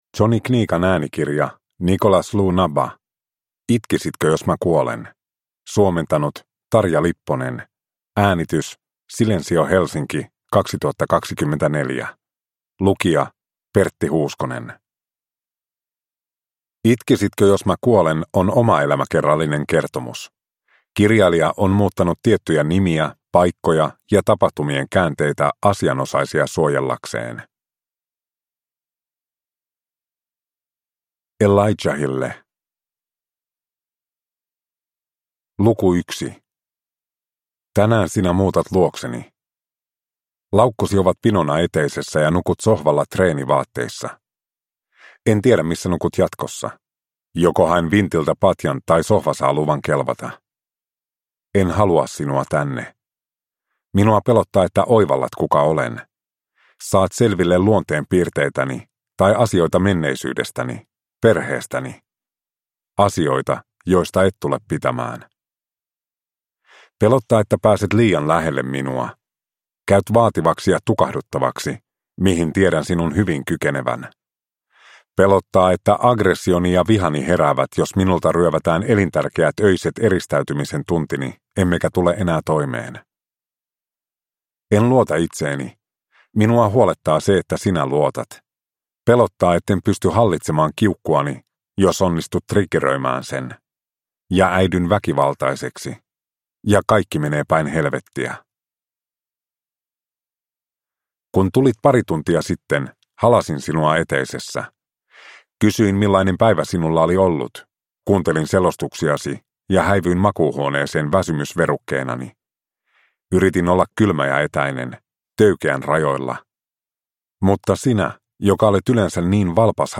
Itkisitkö, jos mä kuolen? (ljudbok) av Nicolas Lunabba